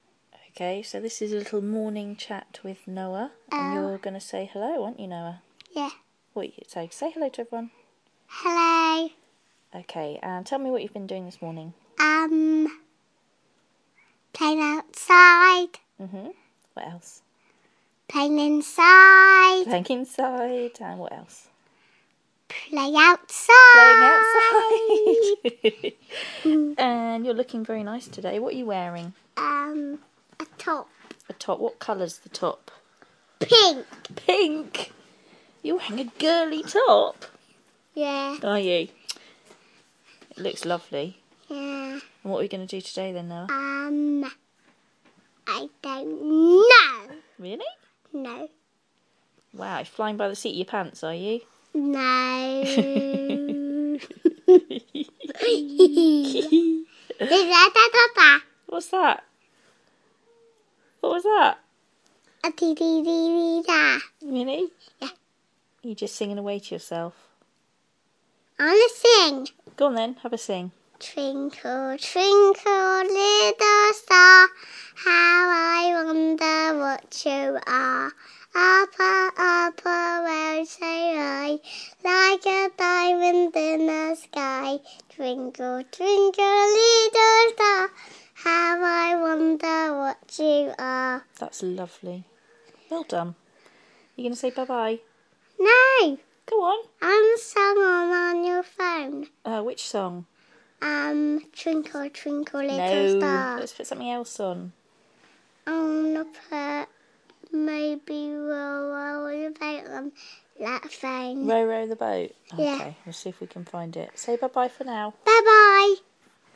Morning Interview